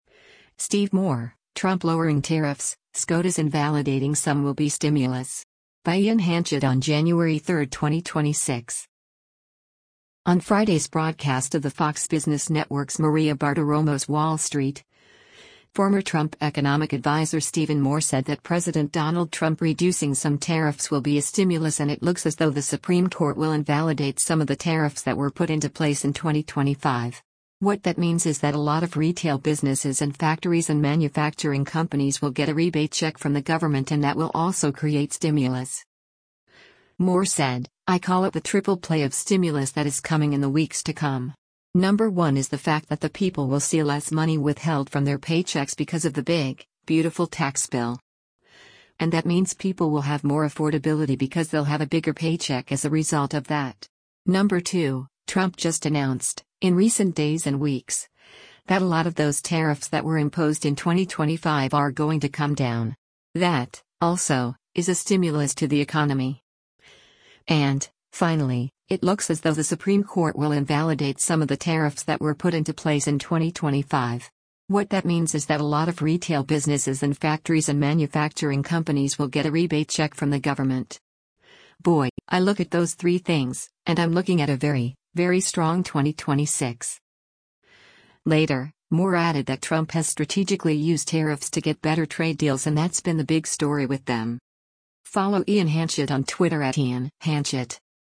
On Friday’s broadcast of the Fox Business Network’s “Maria Bartiromo’s Wall Street,” former Trump Economic Adviser Stephen Moore said that President Donald Trump reducing some tariffs will be a stimulus and “it looks as though the Supreme Court will invalidate some of the tariffs that were put into place in 2025.